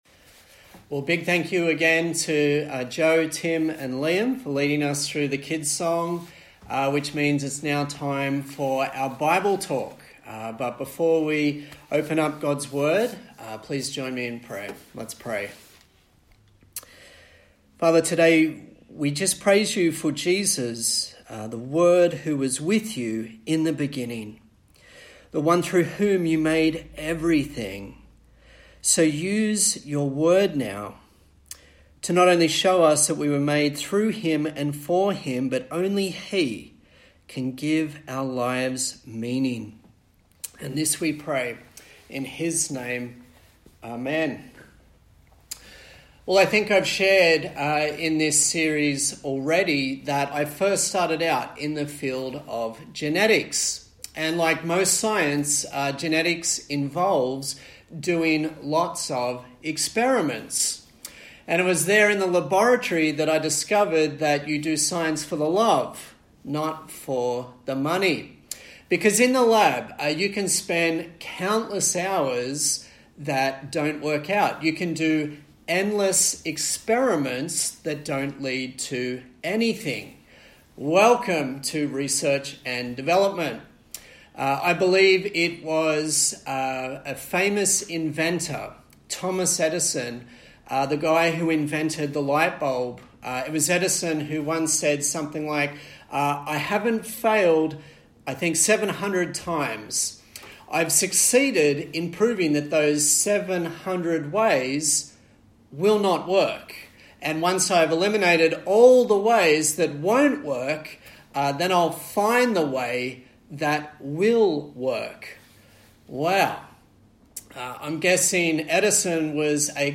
A sermon in the series on the book of Ecclesiastes
Service Type: Sunday Morning